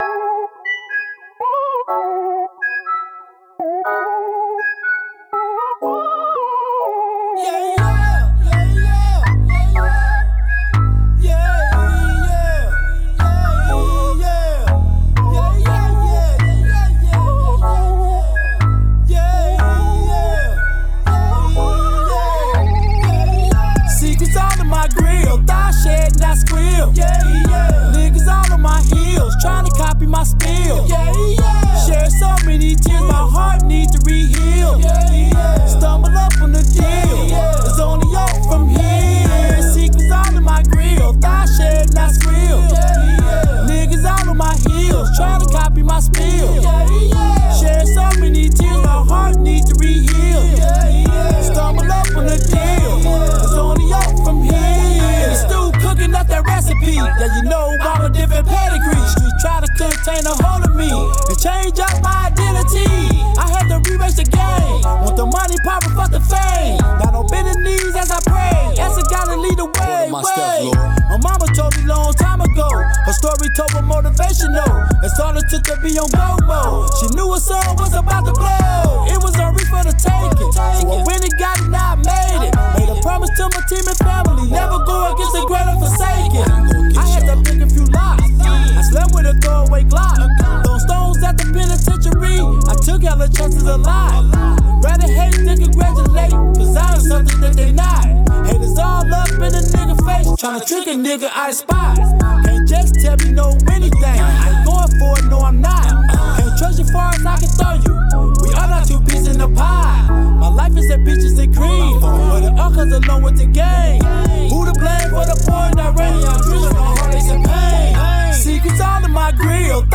Rap
A smooth sound with a rhythm flow from The MIDWEST..